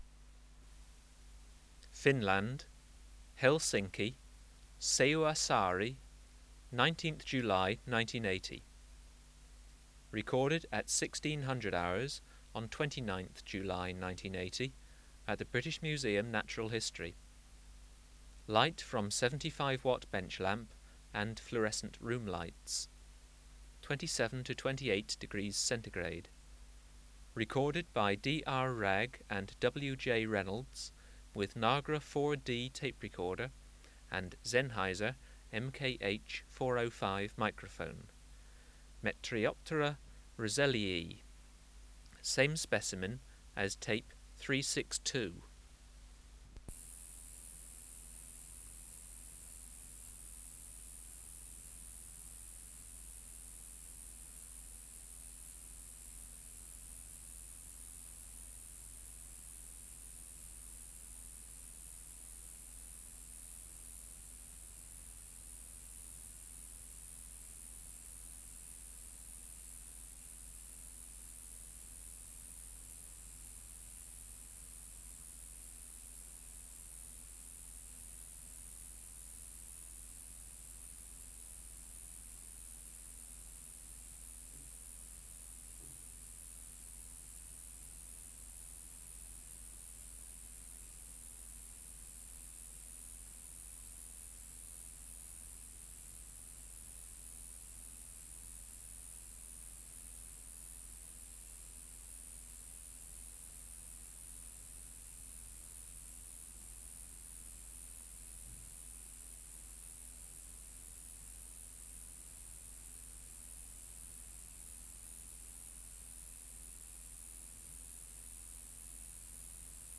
Project: Natural History Museum Sound Archive Species: Metrioptera roeselli
Recording Location: BMNH Acoustic Laboratory
Reference Signal: 1 kHz for 10 s
Substrate/Cage: Small recording cage
Microphone & Power Supply: Sennheiser MKH 405 Distance from Subject (cm): 12
Recorder: Kudelski Nagra IV-D (-17dB at 50Hz)